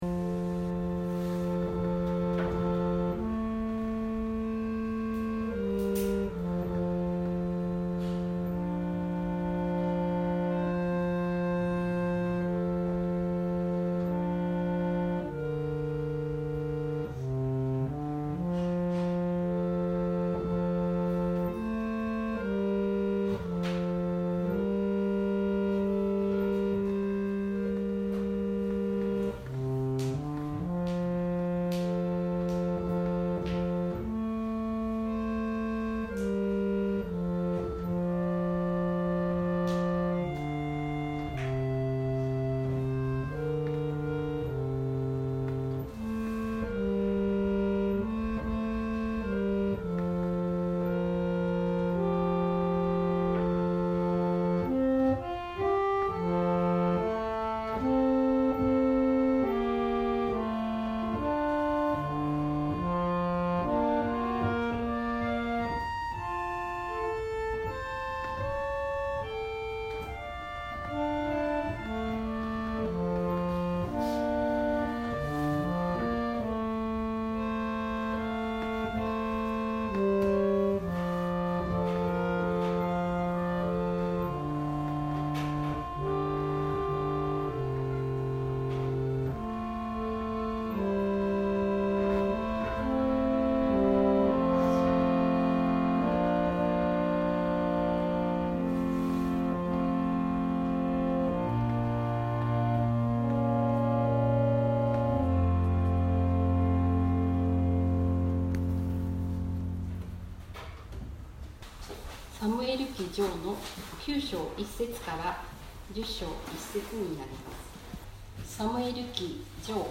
千間台教会。説教アーカイブ。
音声ファイル 礼拝説教を録音した音声ファイルを公開しています。